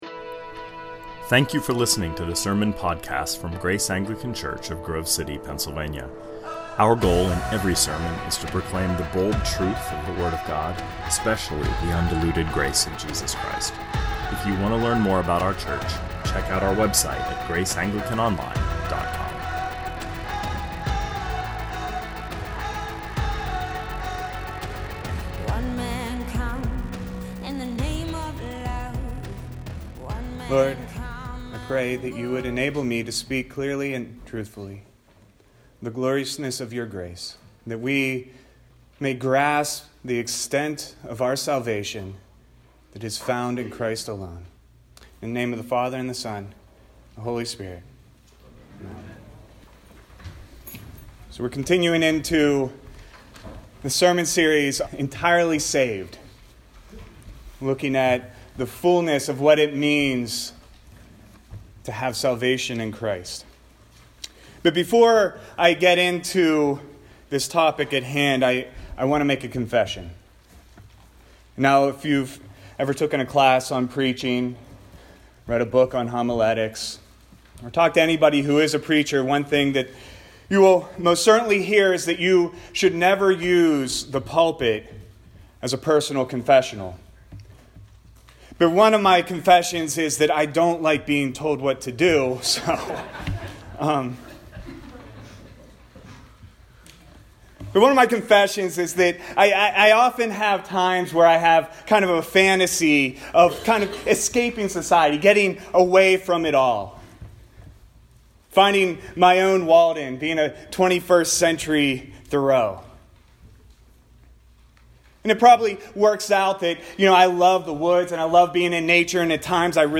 2019 Sermons (Entirely) Saved - Union with Christ -Ephesians 1 Play Episode Pause Episode Mute/Unmute Episode Rewind 10 Seconds 1x Fast Forward 30 seconds 00:00 / 32:54 Subscribe Share RSS Feed Share Link Embed